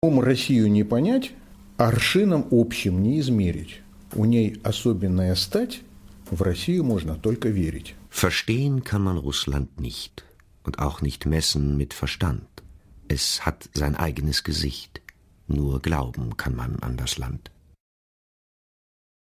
warme und schĂ¶ne ErzĂ€hlerstimme, sehr variantenreich und ausdrucksstark. weiches aber klares timbre. ideal geeigent fĂŒr hĂ¶rbĂŒcher, hĂ¶rspiele , dokumentationen, voice-over und feature.
Sprechprobe: eLearning (Muttersprache):